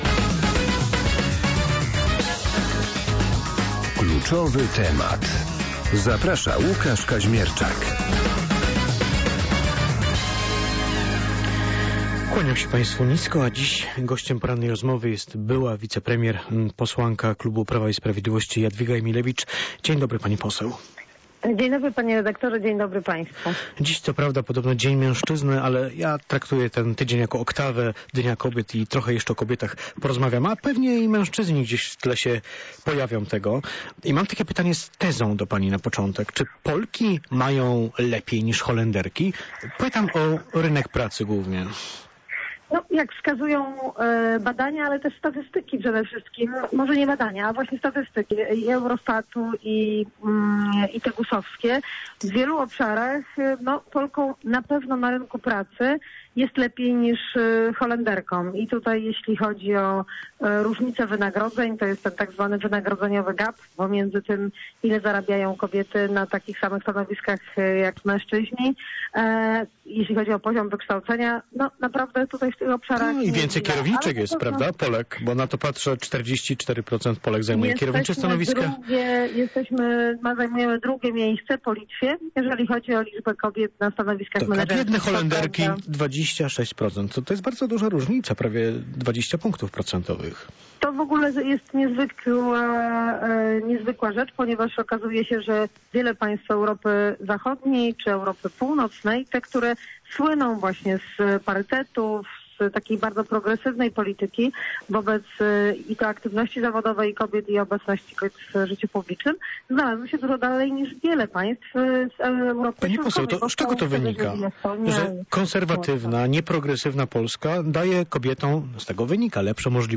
Gościem porannej rozmowy była Jadwiga Emilewicz, była wicepremier, posłanka klubu Prawa i Sprawiedliwości.